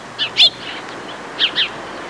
Blackbird Sound Effect
Download a high-quality blackbird sound effect.
blackbird-3.mp3